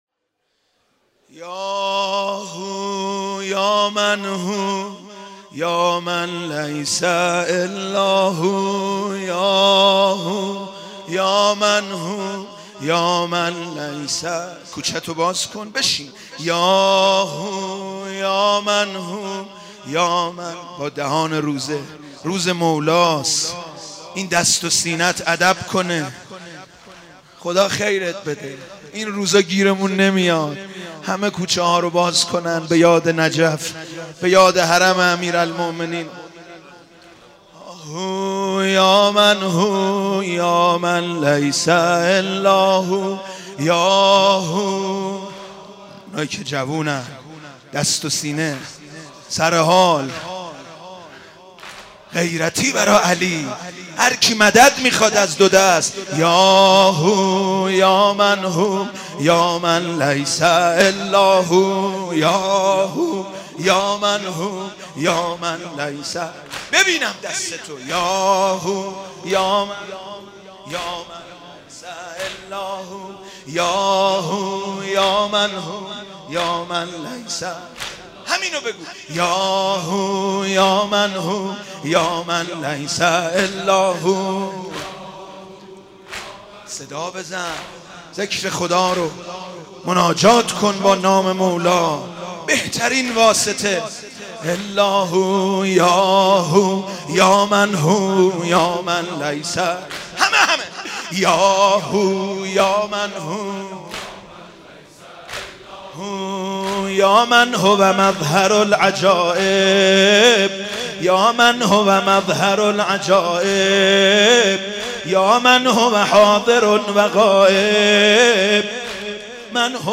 زمینه - روز ٢١ رمضان.mp3